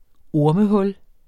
Udtale [ ˈoɐ̯mə- ]